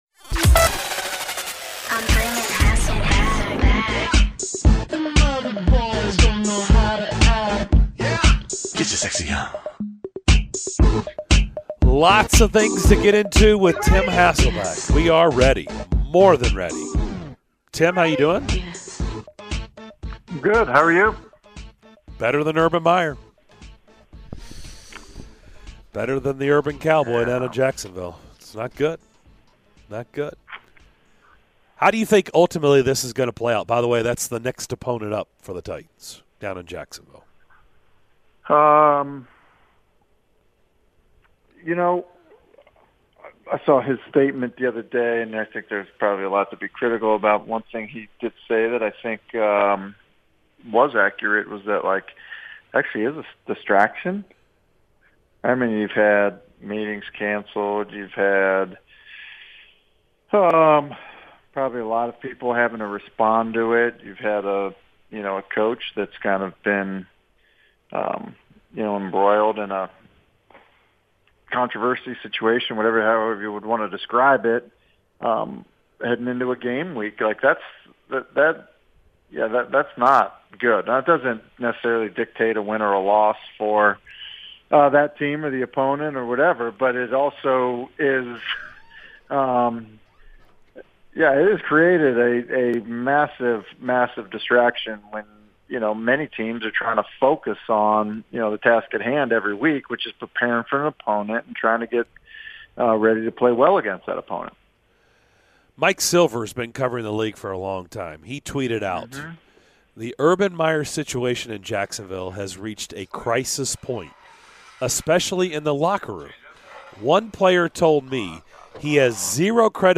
ESPN's Tim Hasselbeck joined the DDC to discuss the latest from around the NFL!